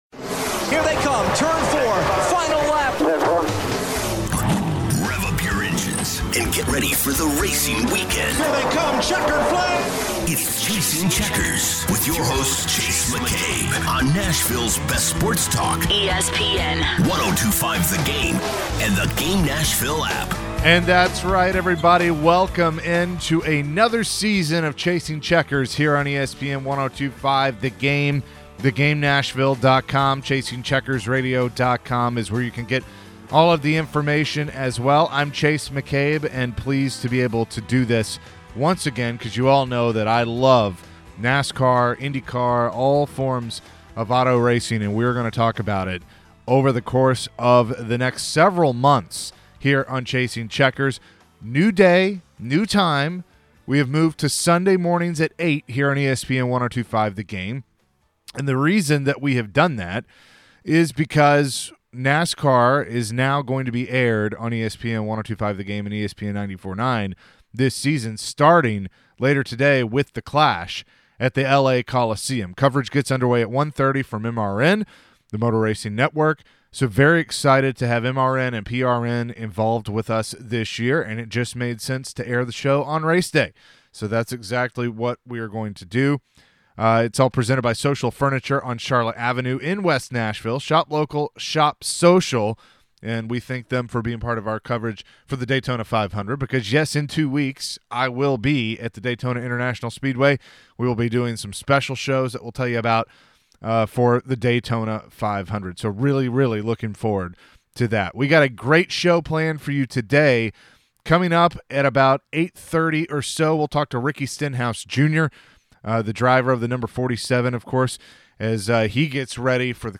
You'll hear from drivers Joey Logano and Ricky Stenhouse Jr. as well.